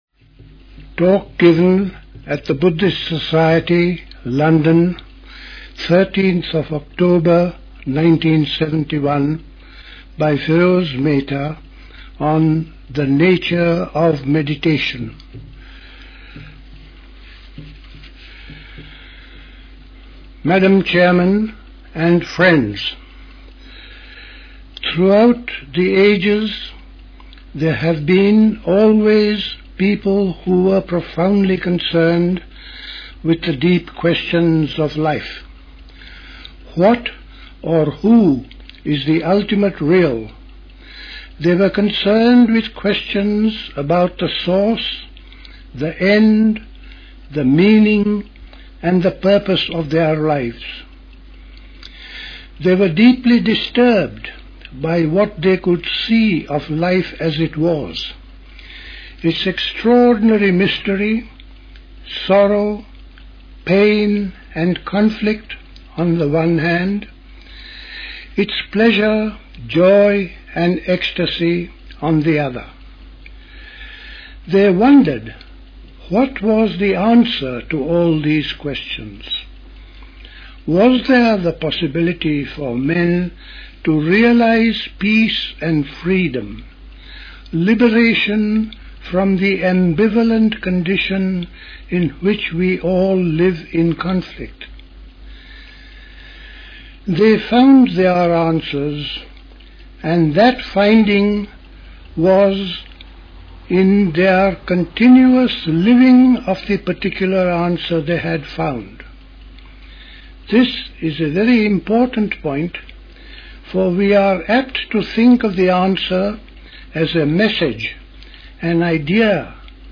Recorded at the 1971 Buddhist Summer School.